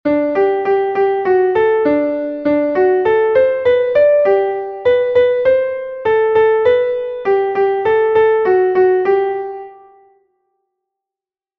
Text und Melodie: Volksweise aus Thüringen, um 1800.